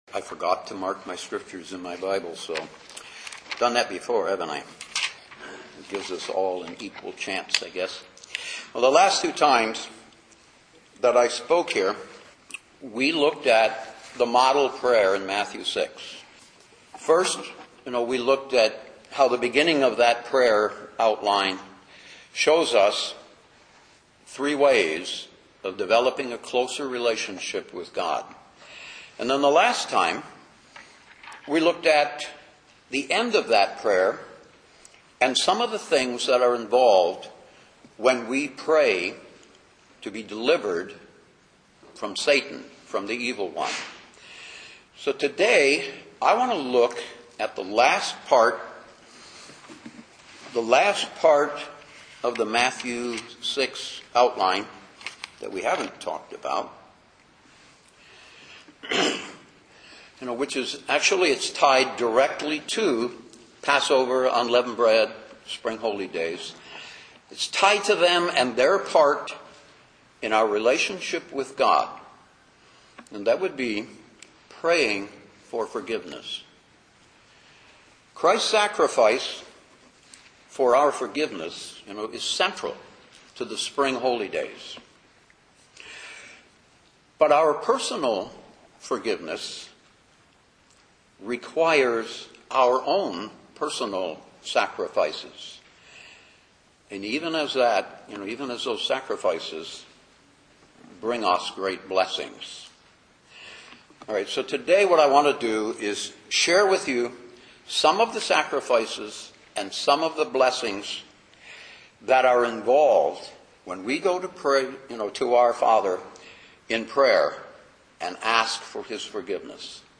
Given in Detroit, MI
UCG Sermon Studying the bible?